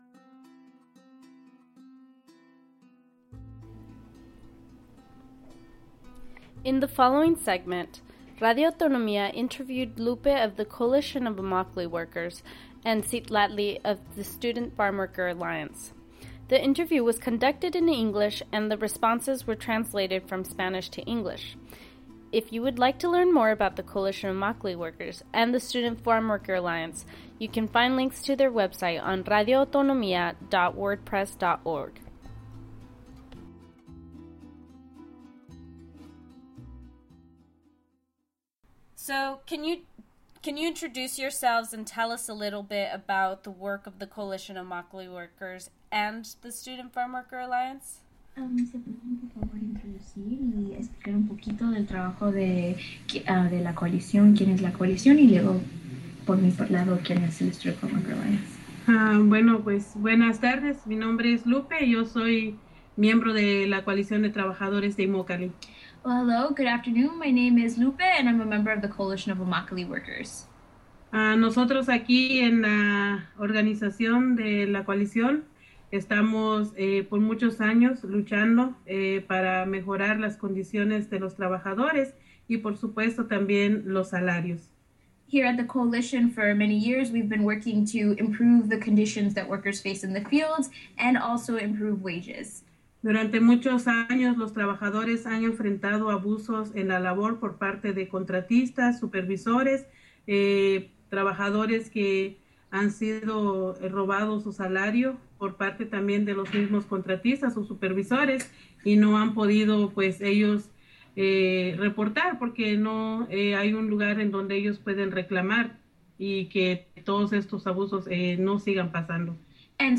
Full Audio plus individual segments from the April 7, 2013 broadcast of Radio Autonomía: Zapatismo in the Bay on Berkeley Liberation Radio, 104.1 FM, livestream at Berkeley Liberation Radio
ciw_sfa_interview.mp3